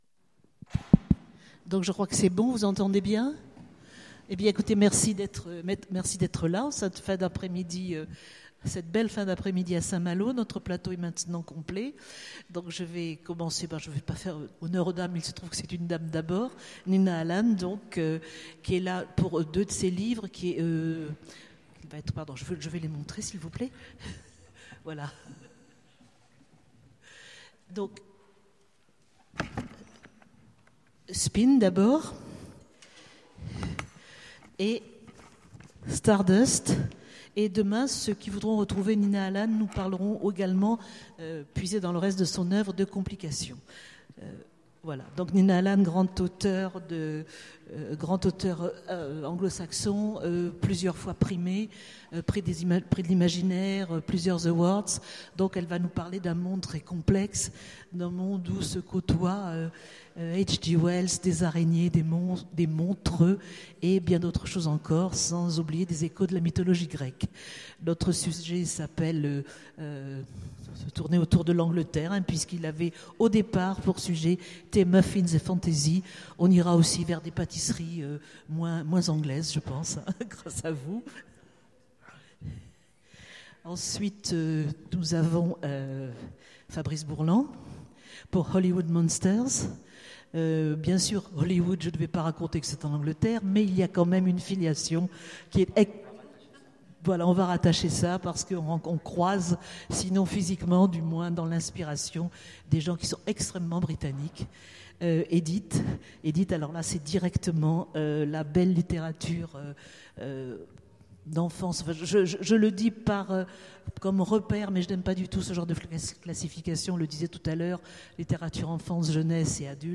Étonnants Voyageurs 2015 : Conférence Thé, muffins et fantastique